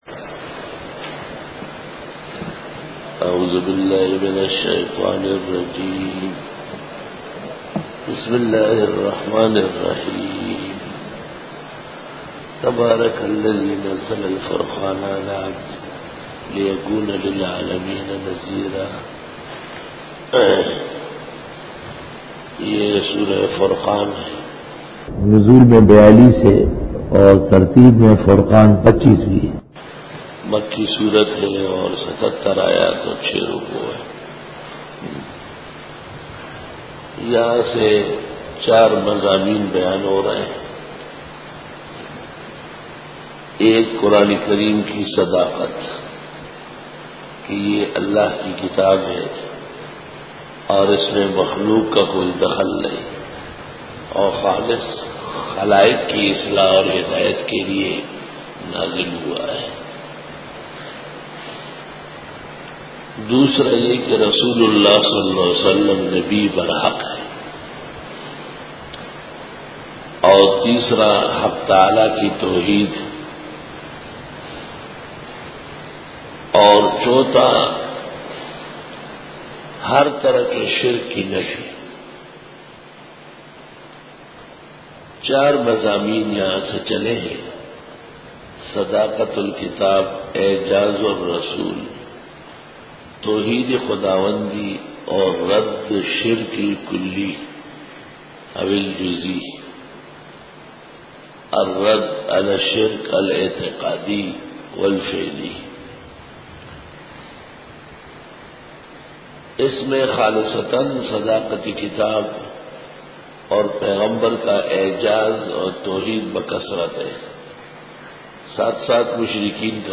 سورۃ الفرقان رکوع-01 Bayan